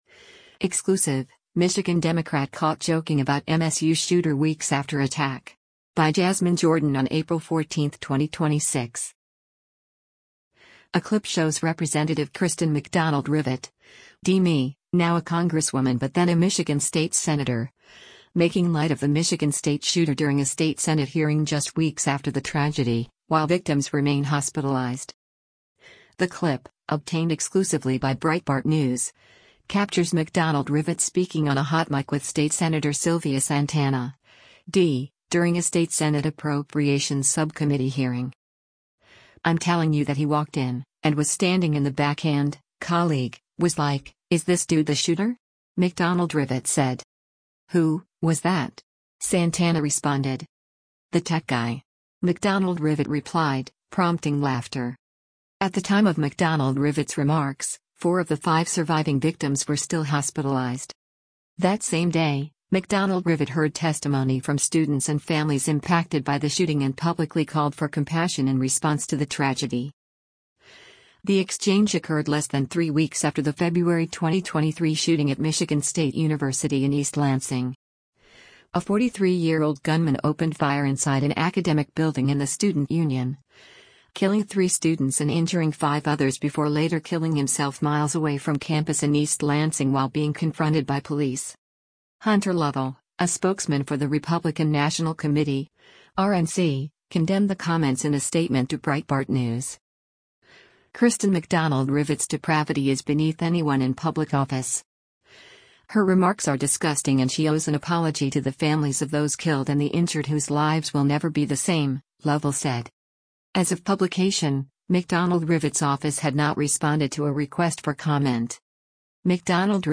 The clip, obtained exclusively by Breitbart News, captures McDonald Rivet speaking on a hot mic with state Sen. Sylvia Santana (D) during a State Senate Appropriations subcommittee hearing.
“The tech guy!” McDonald Rivet replied, prompting laughter.